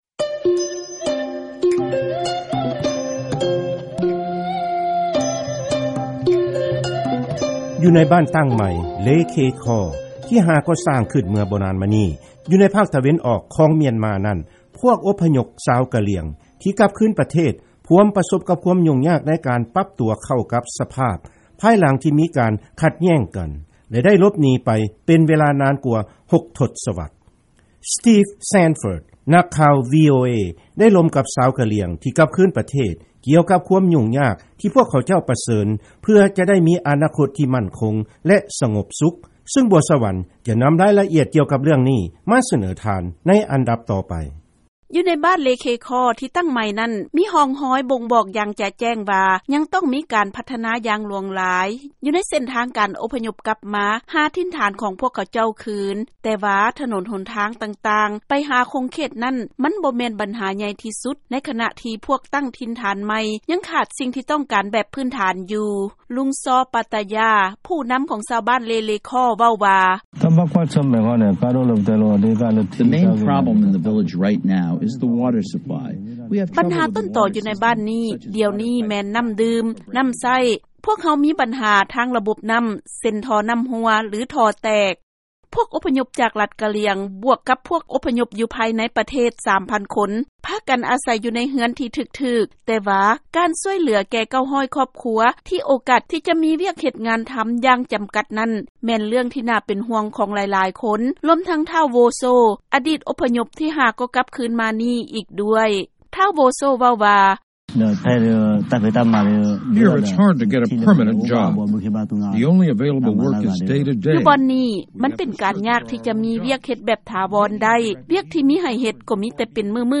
ເຊີນຟັງລາຍງານຄວາມຫຍຸ້ງຍາກໃນການດຳລົງຊີວິດ ຂອງຊາວກະຫລ່ຽງຢູ່ບ້ານຕັ້ງໃໝ່ຂອງເຂົາເຈົ້າ